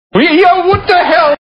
Category: Television   Right: Personal
Tags: David Letterman Letterman David Letterman clips tv talk show great audio clips